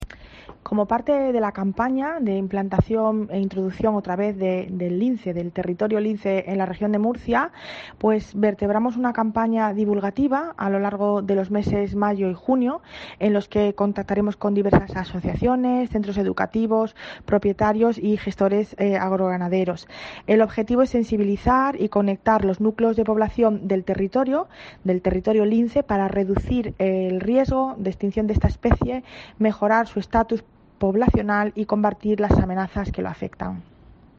Mari Cruz Ferreira, directora general del Medio Natural